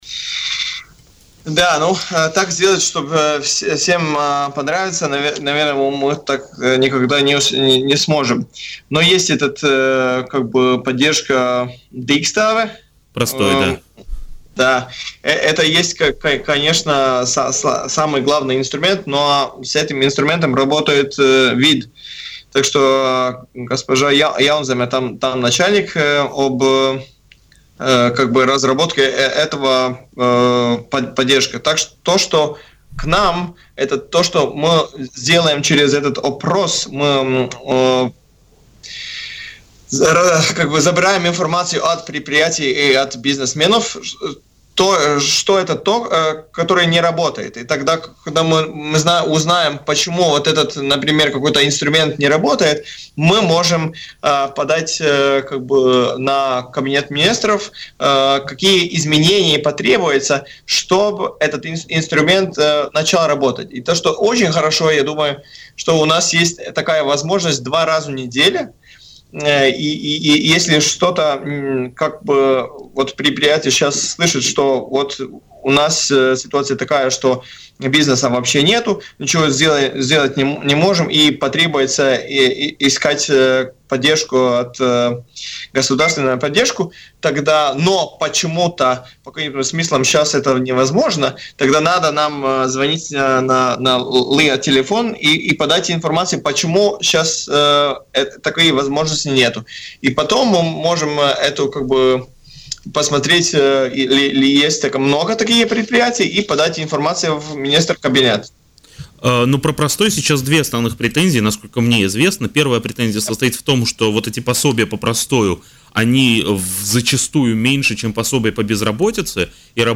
Директор Латвийского агентства инвестиций и развития (LIAA) Каспарс Рожкалнс в эфире радио Baltkom рассказал о том, как именно агентство оказывает помощь малым и средним предприятиям во время кризиса, вызванного пандемией COVID-19.